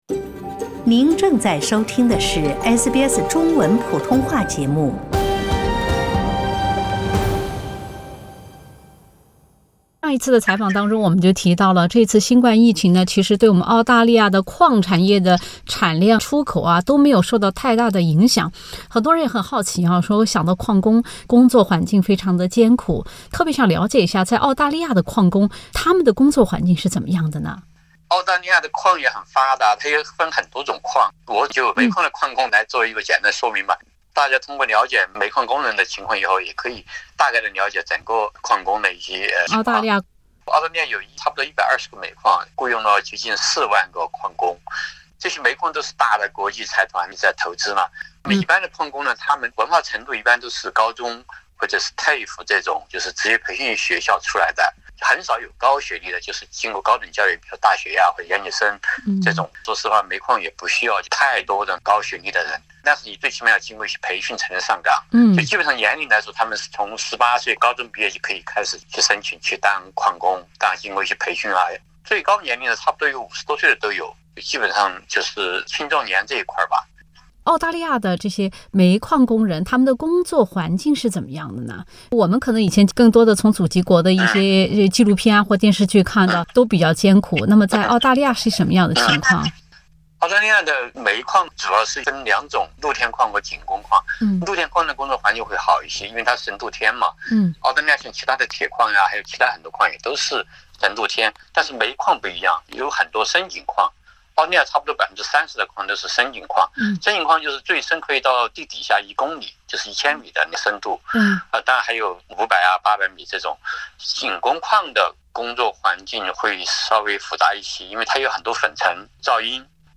业内人士讲述澳洲煤矿工人的薪资、福利和工作环境。